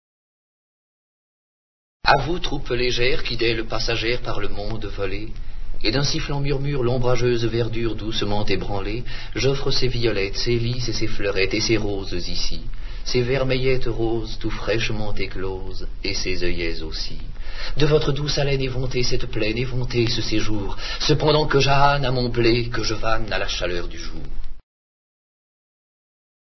dit par Jean DESAILLY